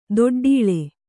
♪ doḍḍīḷe